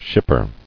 [ship·per]